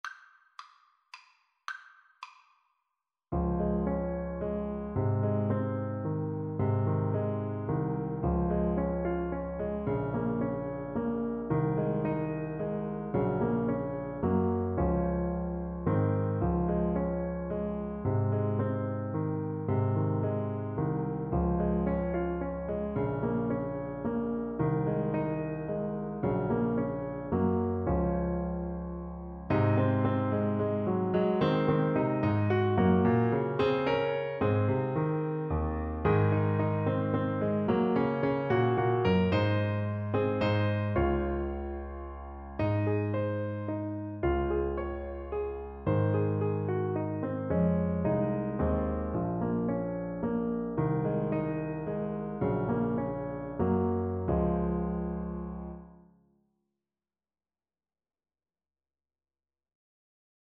Alto Saxophone
Gently Rocking = c. 110
3/4 (View more 3/4 Music)